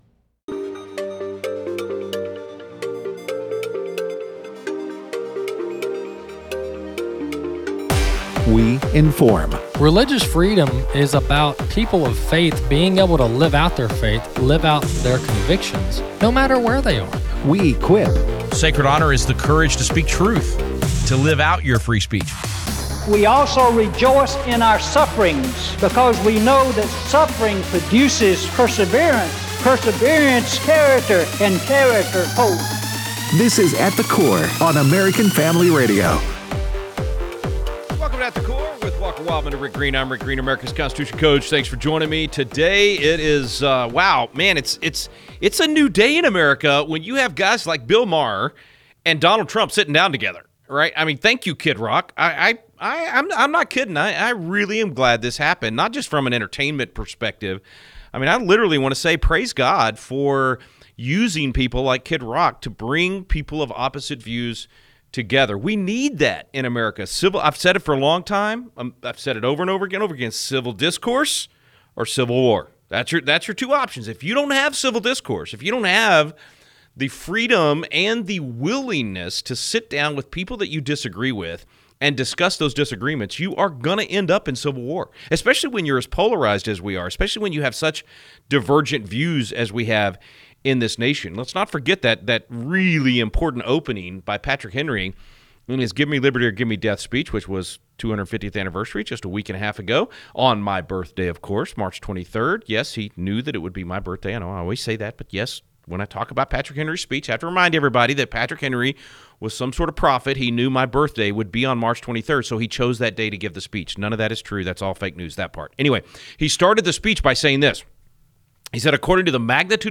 Callers share about the tariffs